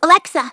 synthetic-wakewords
ovos-tts-plugin-deepponies_Trixie_en.wav